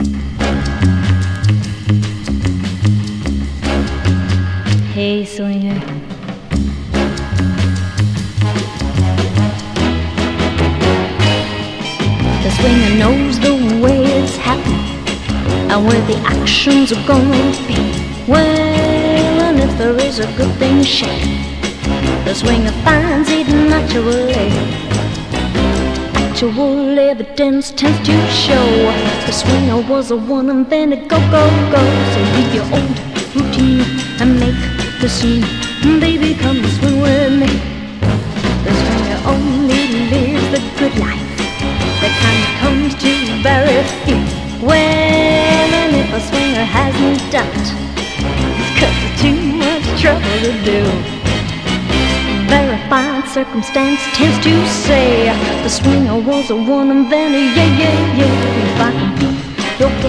EASY LISTENING / VOCAL / CHORUS
混声コーラス・グループによるミュージカル・カヴァー集！
メランコリックなチェンバロのイントロも心弾む